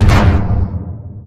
boomout.wav